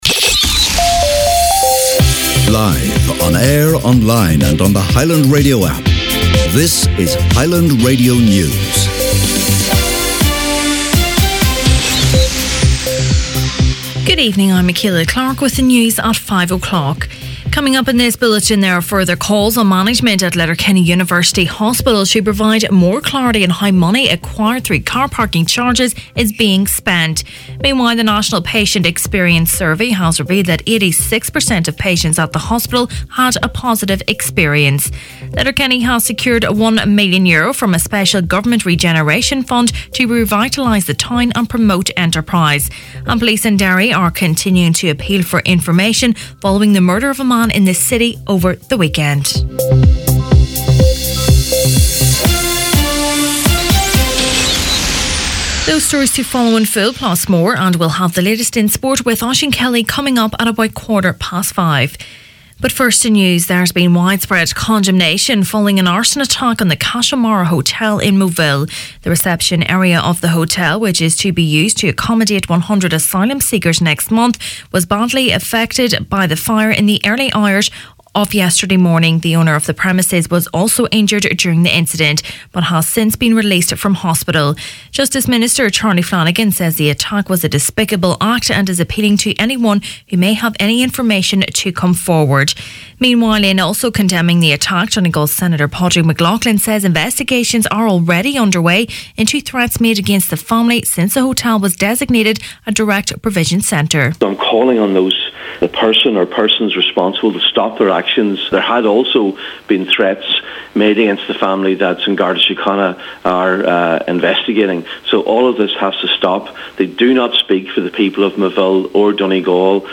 Main Evening News, Sport and Obituaries Monday November 26th